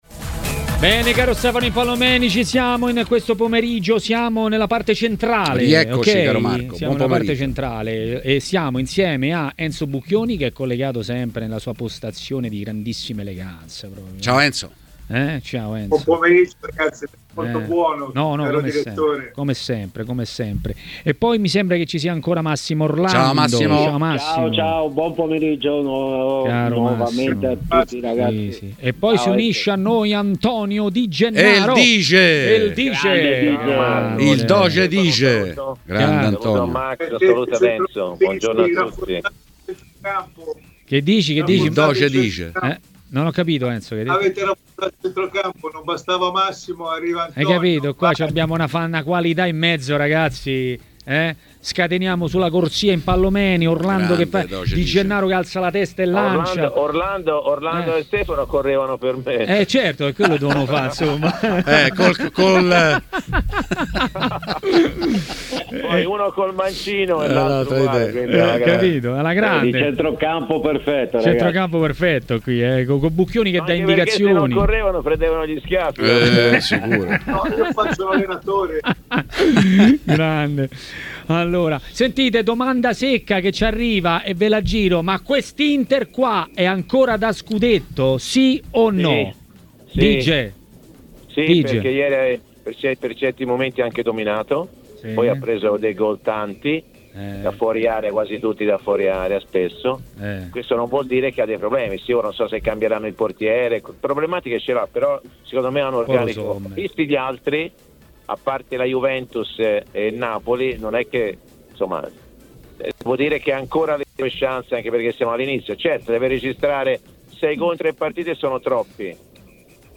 A Maracanà, nel pomeriggio di TMW Radio, ha parlato Antonio Di Gennaro, ex calciatore e commentatore tv.